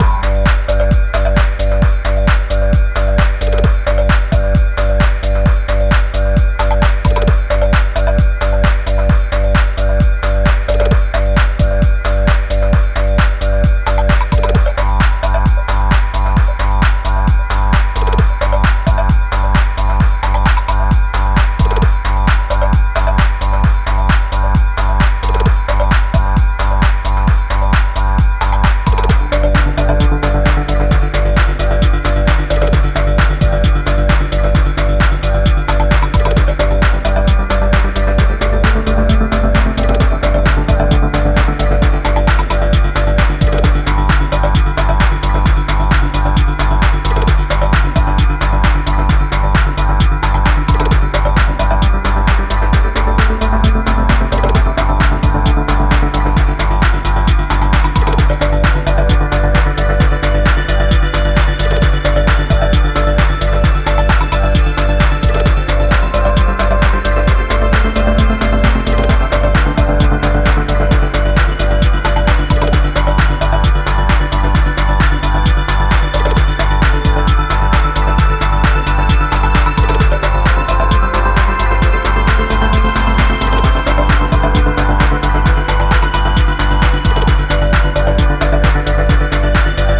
軽快にシャッフルしたパーカッシヴビートにアップリフティングなミニマルリフ、ユーフォリックなストリングスをあしらった